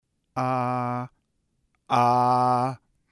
La vocal [a] en volumen bajo y alto.